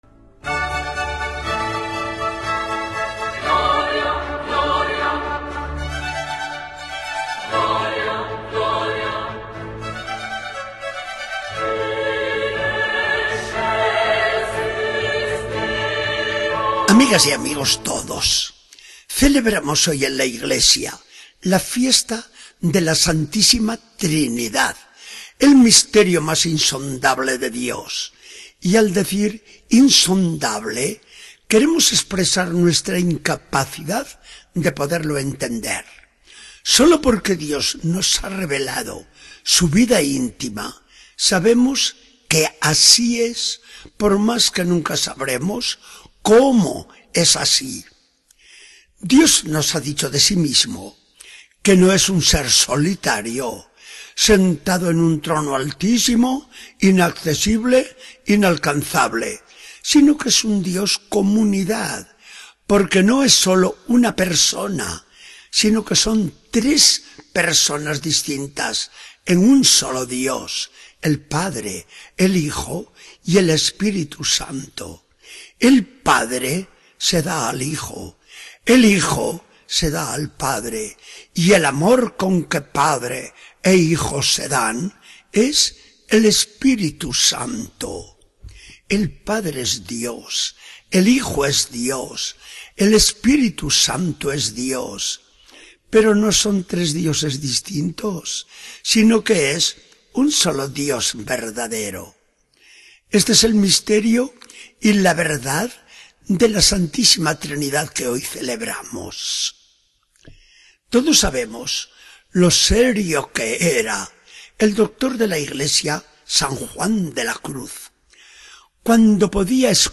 Charla del día 15 de junio de 2014. Del Evangelio según San Juan 3, 16-18.